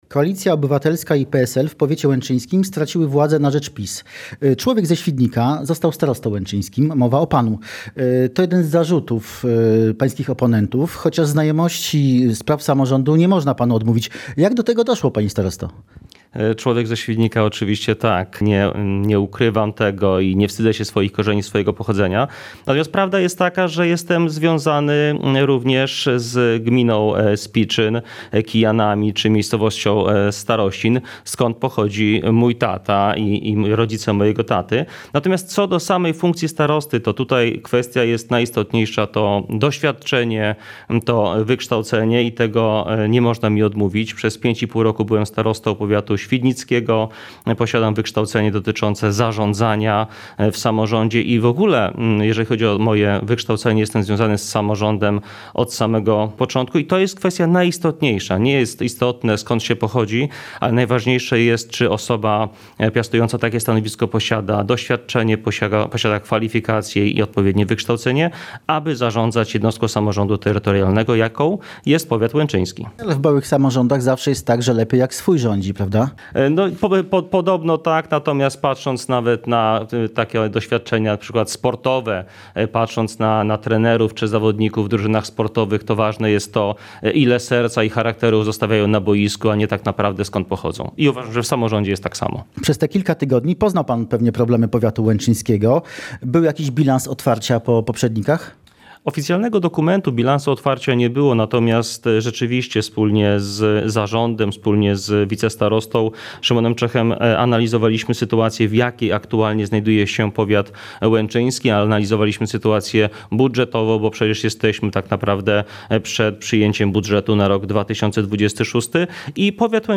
Rozmowa ze starostą łęczyńskim Łukaszem Reszką